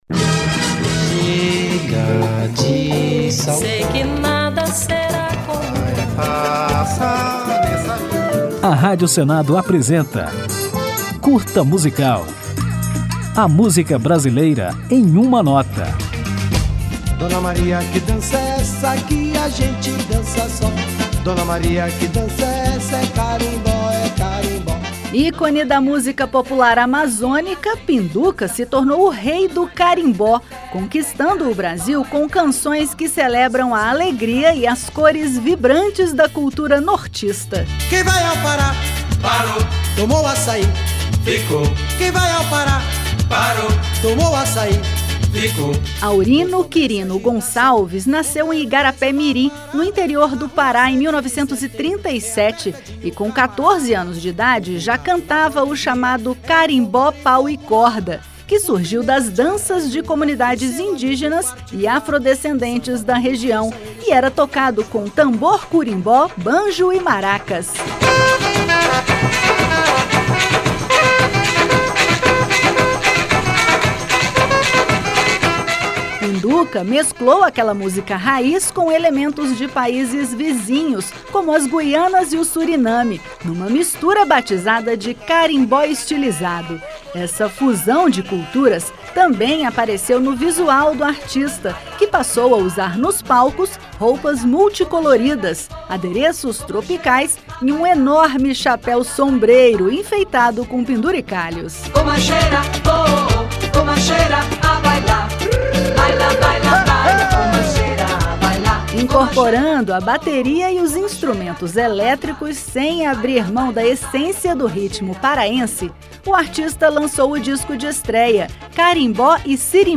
Ao final, vamos ouvir o maior sucesso de Pinduca, a música Sinhá Pureza.